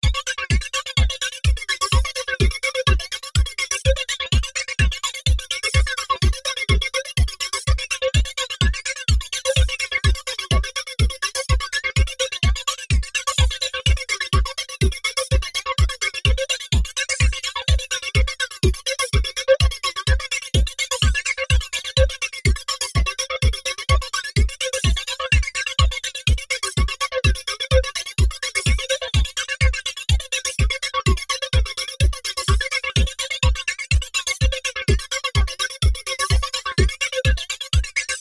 ダンス、メタル、金属、エレクトロニック・ダンス・ミュージック。